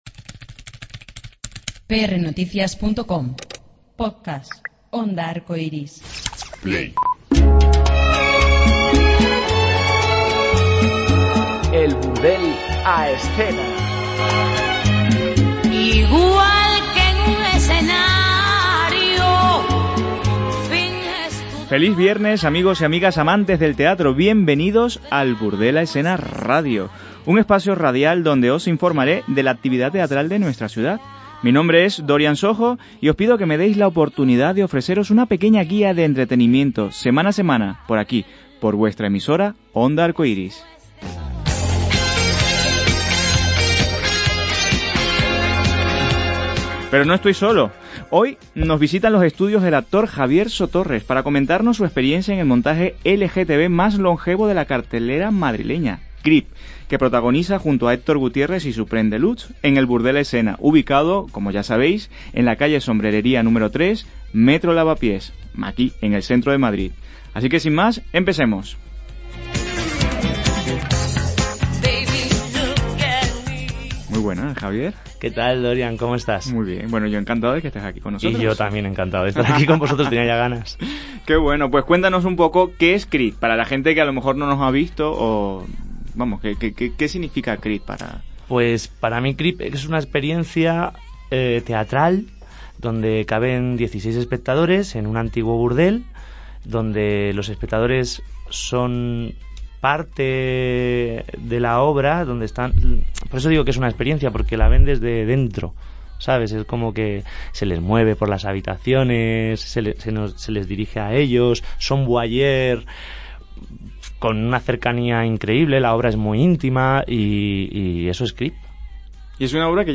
Enterarte más de este guapo y talentoso actor, en nuestra entrevista de esta semana.